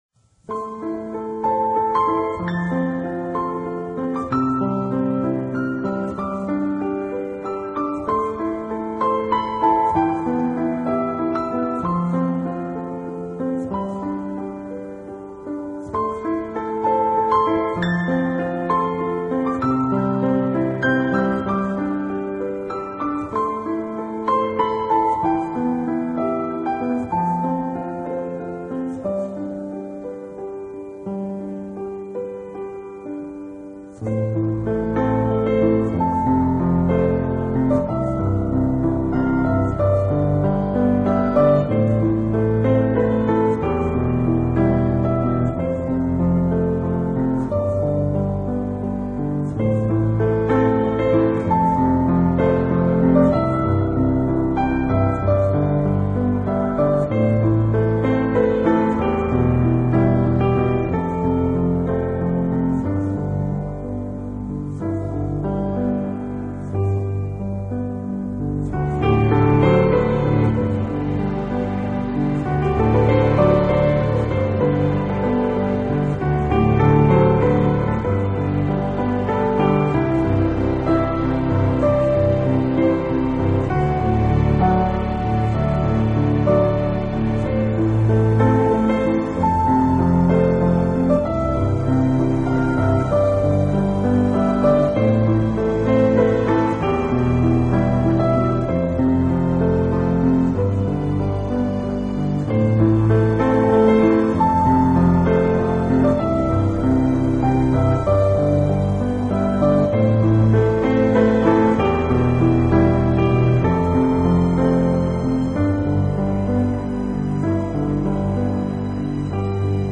包含了12首唯美的钢琴音乐。